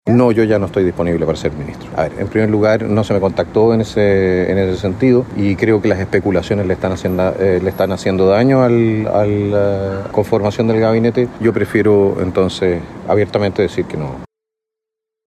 Si yo hubiese sido en algún momento una persona interesante para ser ministro, ya habrían hablado conmigo”, afirmó, en diálogo con Mesa Central de Canal 13.